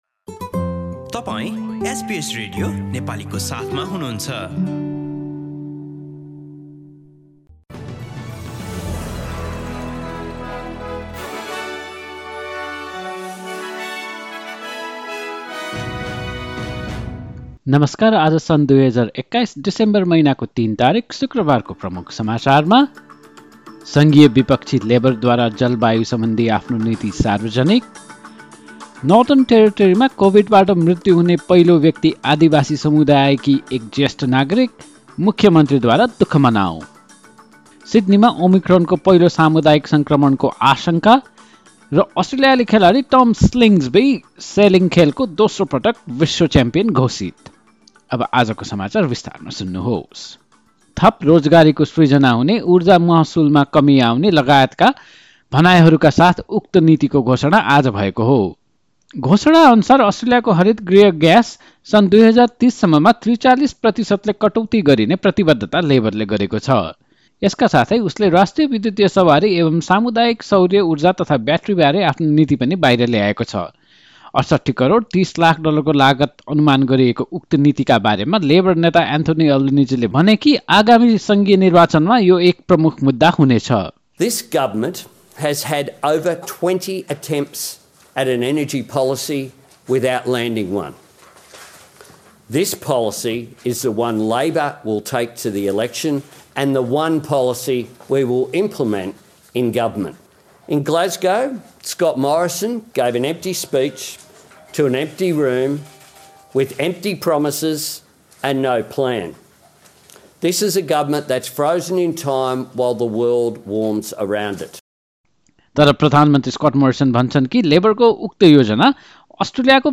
एसबीएस नेपाली अस्ट्रेलिया समाचार: शुक्रवार ३ डिसेम्बर २०२१